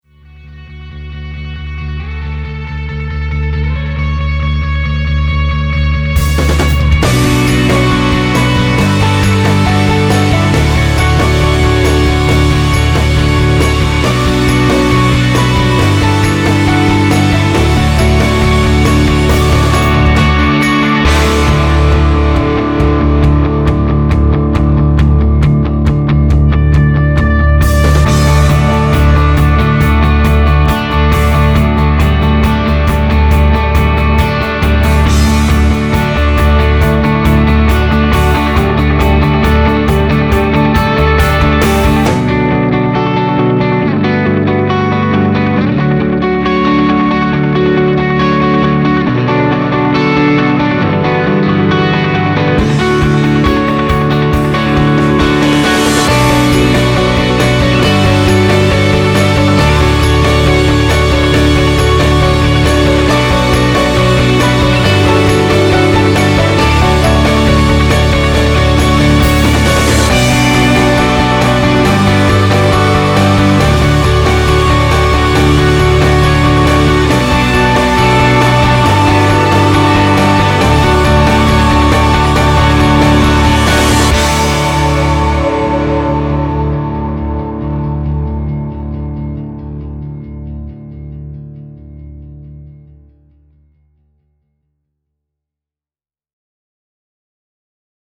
Indie, Rock